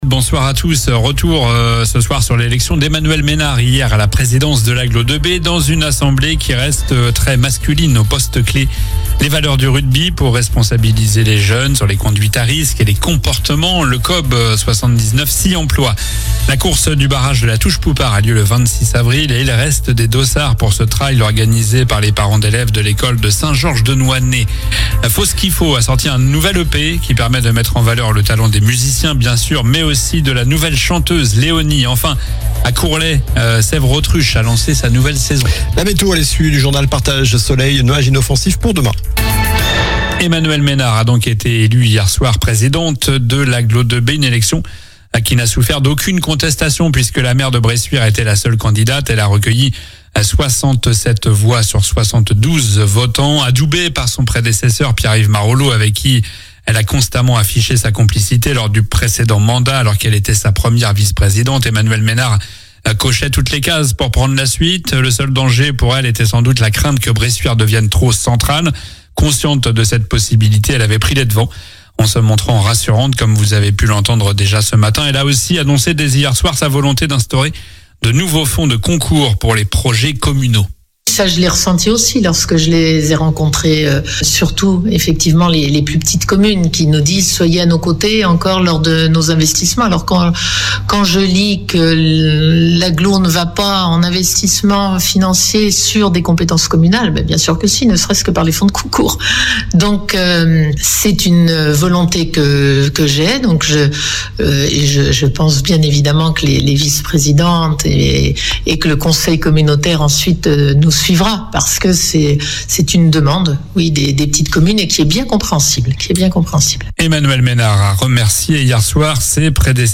COLLINES LA RADIO : Réécoutez les flash infos et les différentes chroniques de votre radio⬦
Journal du mercredi 15 avril (soir)